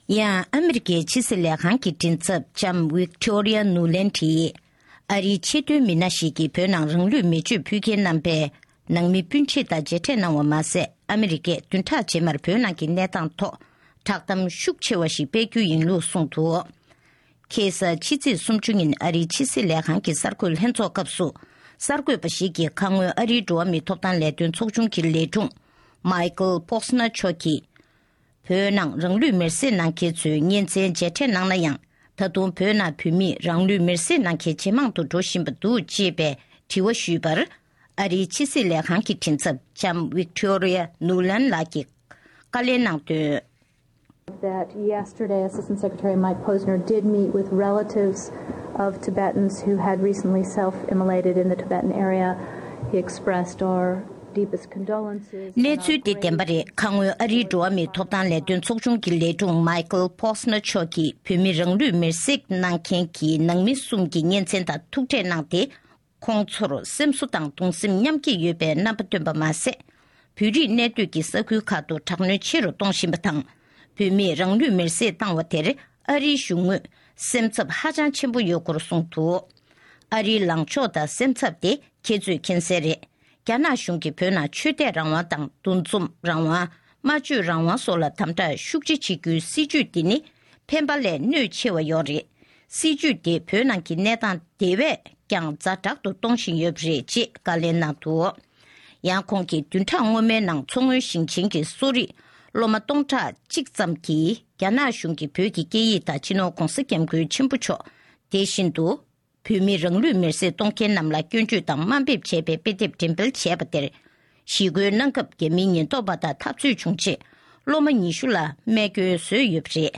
བོད་དོན་བསྒྲགས་གཏམ་སྤེལ་རྒྱུ། ཨ་རིའི་ཕྱི་སྲིད་ལས་ཁང་གི་མགྲིན་ཚབ་ལྕམ་བིག་ཊོ་རི་ཡ་ནུ་ལེན་ཌ་ཡིས་གསར་འགོད་ལྷན་ཚོགས་ཐོག་བཀའ་མོལ་གནང་བཞིན་པ།
སྒྲ་ལྡན་གསར་འགྱུར།